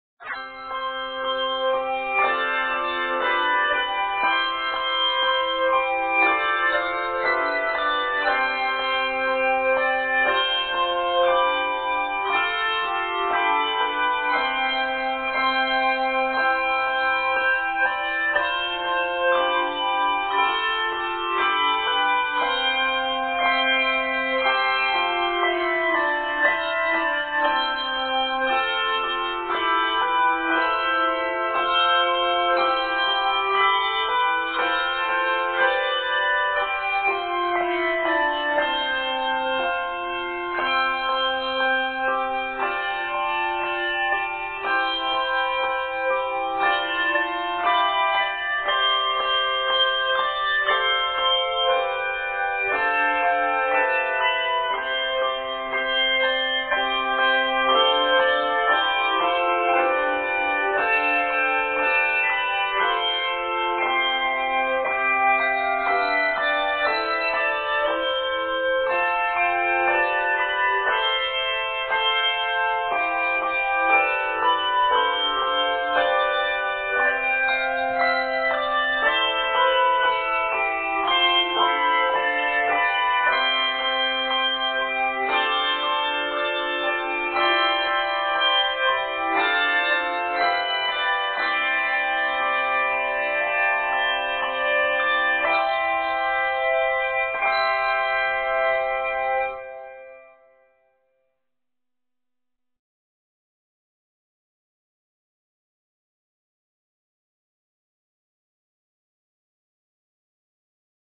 Arranged in C Major, measures total 49.